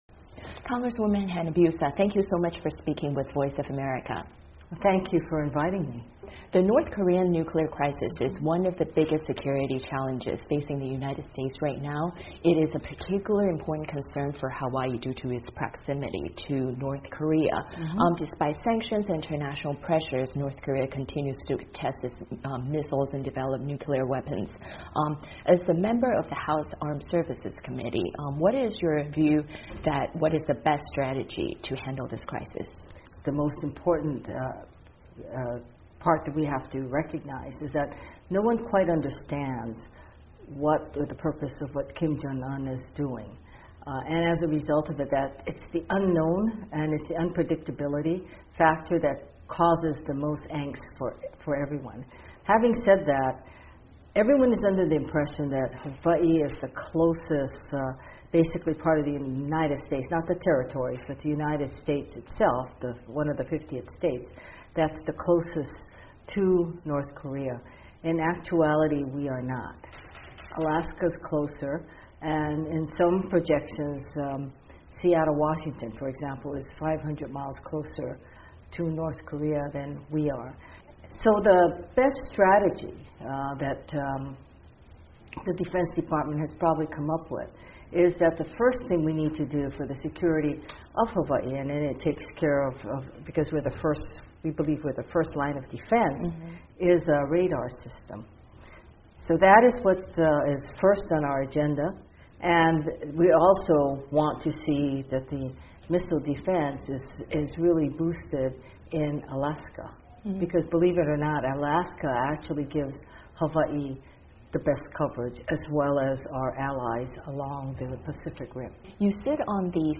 专访日裔美议员谈川普政策对亚裔美国人影响